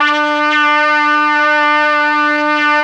RED.BRASS 20.wav